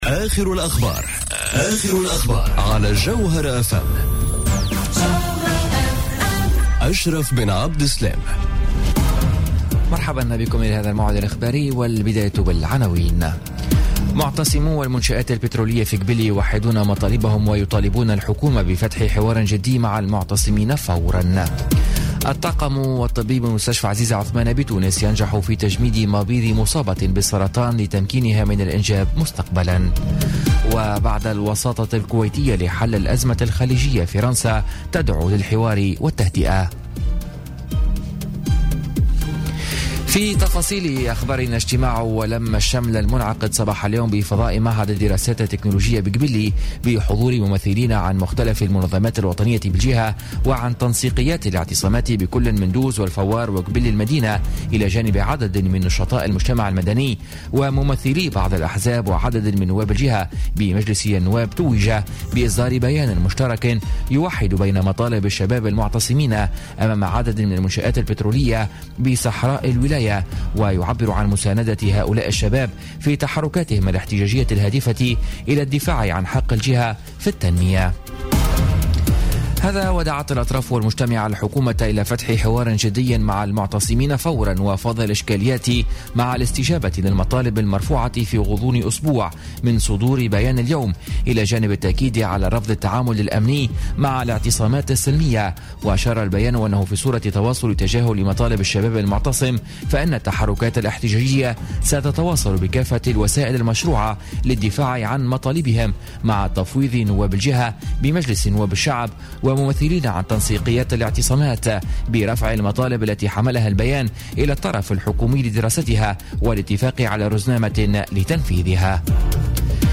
نشرة أخبار السابعة مساء ليوم السبت 15 جويلية 2017